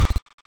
Glitchheartbeat.ogg